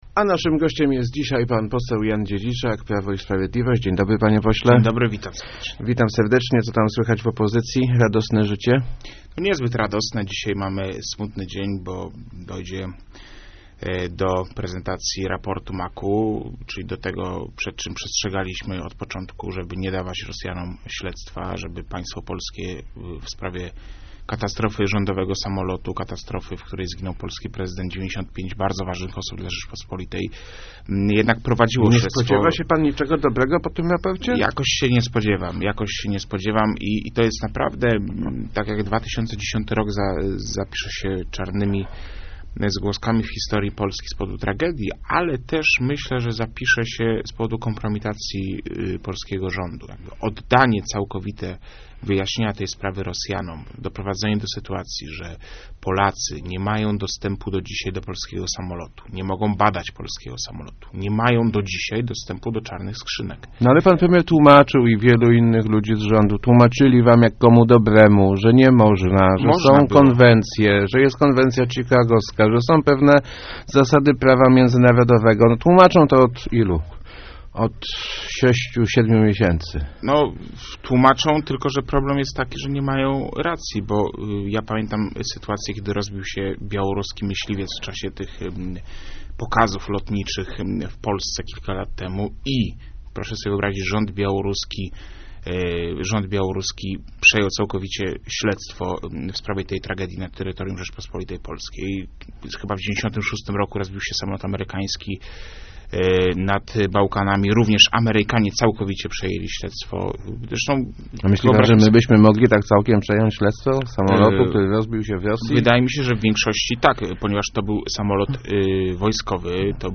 To moja osobista opinia, ale boję się, że nigdy już nie poznamy prawdy o katastrofie smoleńskiej - mówił w Rozmowach Elki poseł PiS Jan Dziedziczak. Parlamentarzysta dodaje jednak, że PiS nigdy nie zrezygnuje z prób wyjaśnienia przyczyn tragedii.